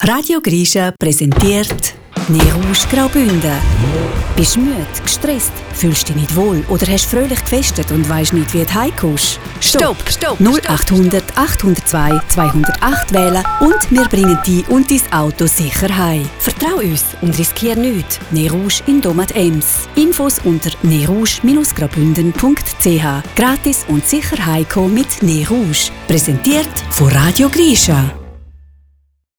Radiobericht Dezember 2024